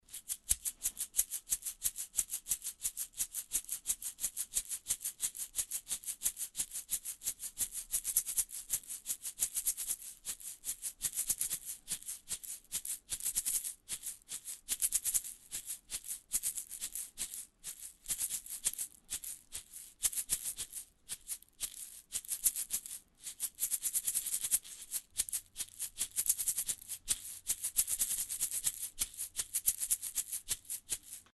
seed shaker - egg shaker.mp3
Recorded with a Steinberg Sterling Audio ST66 Tube, in a small apartment studio.
seed_shaker_-_egg_shaker_j2d.ogg